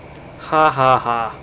Hahaha Sound Effect
hahaha.mp3